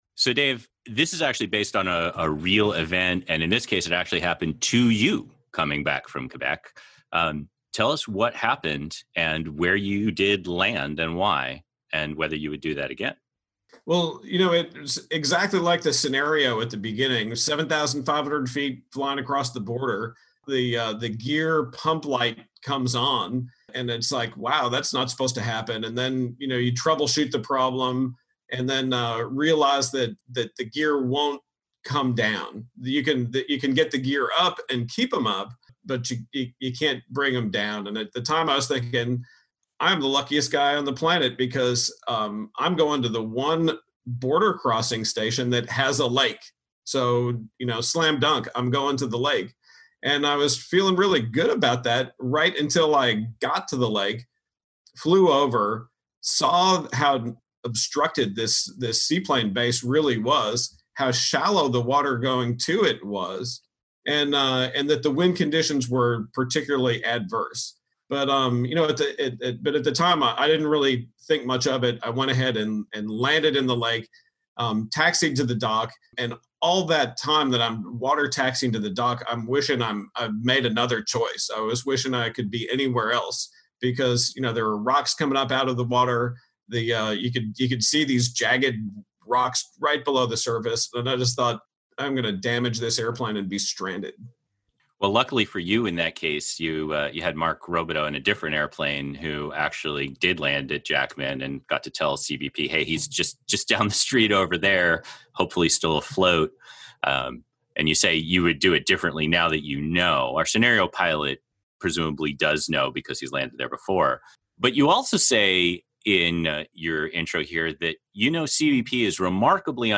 By_Land_or_Sea_rountable final.mp3